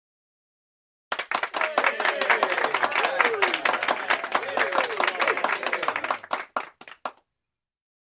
Download Applause Cheer sound effect for free.
Applause Cheer